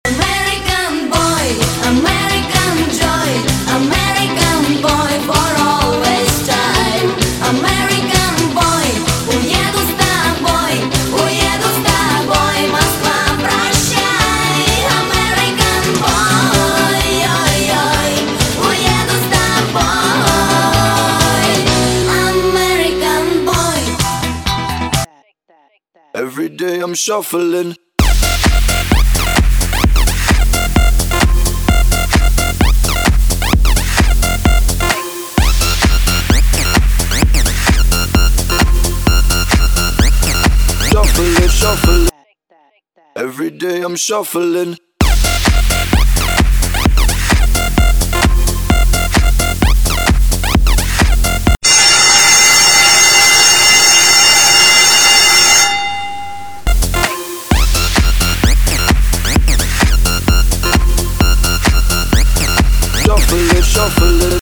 ВЫСТУПЛЕНИЕ!!